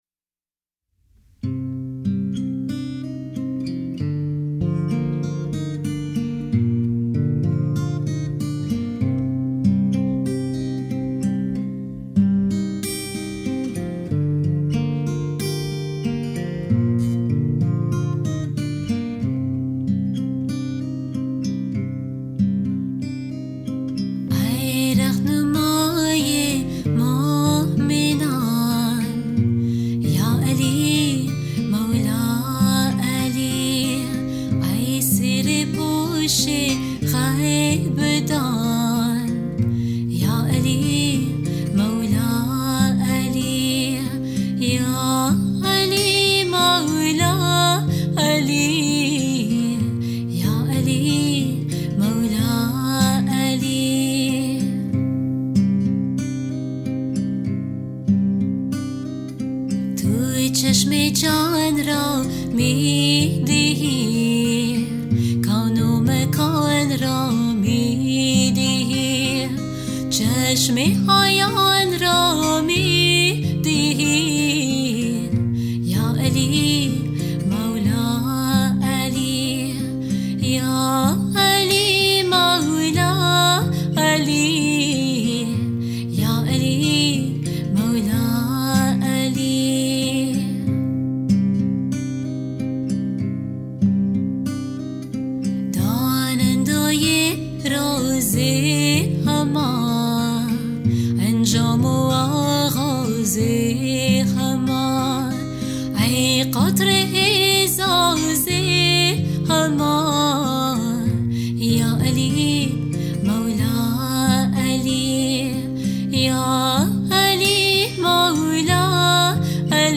Genre: Qasida with Music
Stereo